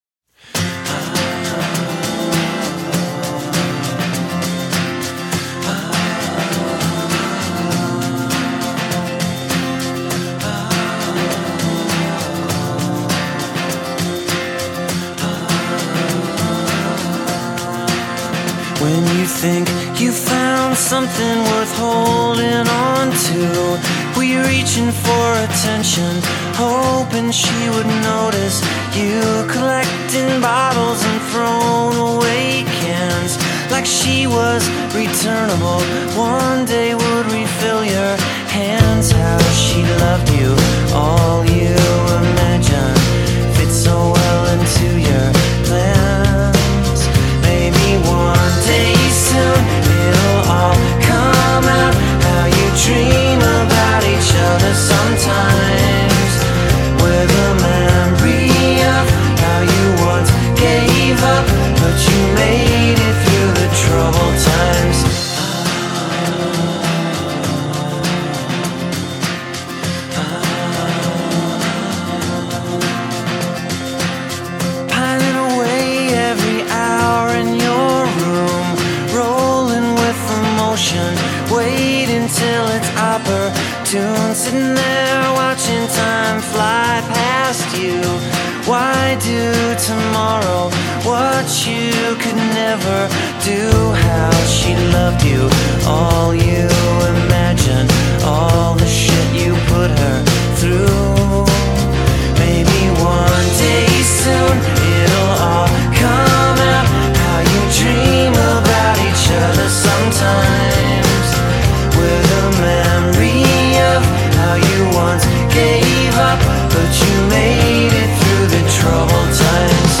straight-up heartbreak song
a soaring chorus